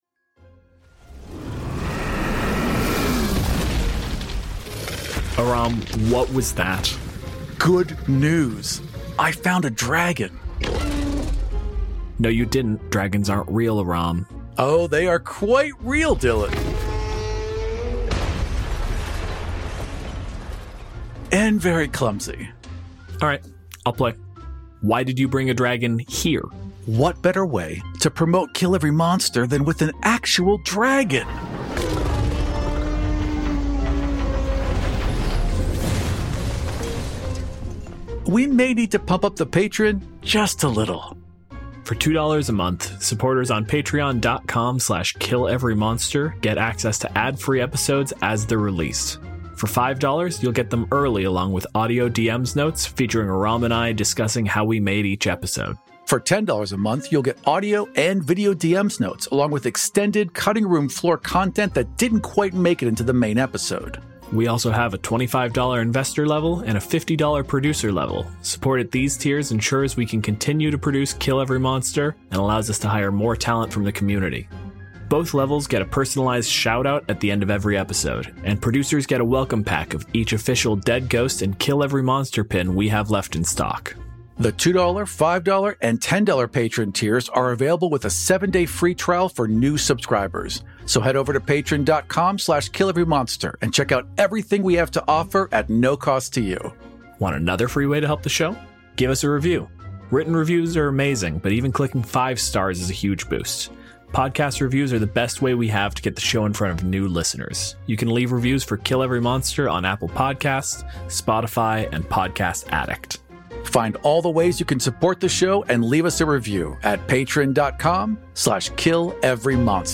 The show Mars Colony is a fully produced actual play podcast following tech billionaire Kelly Perkins as he is summoned to Mars in an effort to save the failing colony. The series explores how the unyielding demands of capitalism combined with the narcissism of silicon valley put humanity on a path to the red planet long before we were ready.